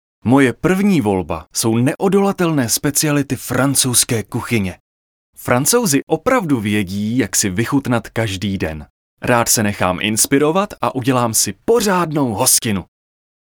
V plně vybaveném domácím nahrávacím studiu pro vás namluvím s expresní rychlostí a profesionalitou prakticky cokoliv dle vašeho přání a podmínek, na kterých se domluvíme.
Jsem profesionální herec a zpěvák s plně vybaveným domácím nahrávacím studiem a zkušenostmi, díky kterým mohu zaručit ten nejlepší výsledek.
8. TV REKLAMA-Kaufland.mp3